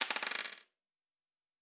Dice Single 2.wav